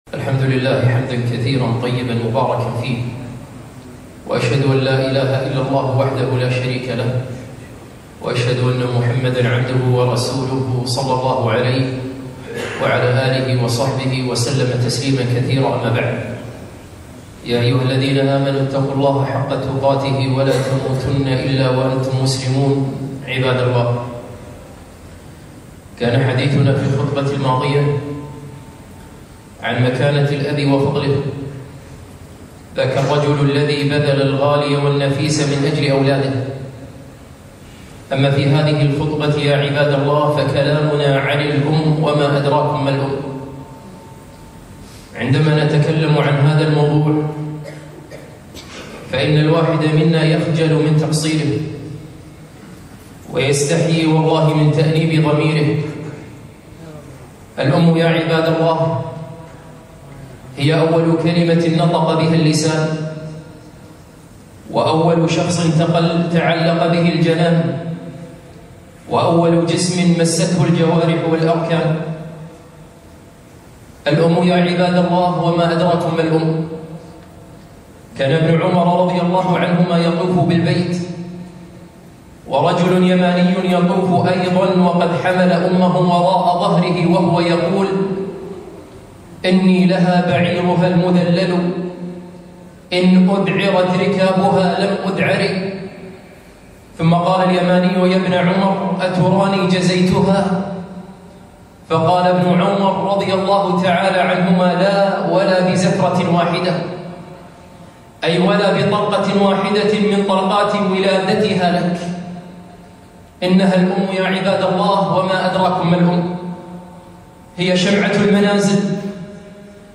خطبة - الأم وما أدراك ما الأم؟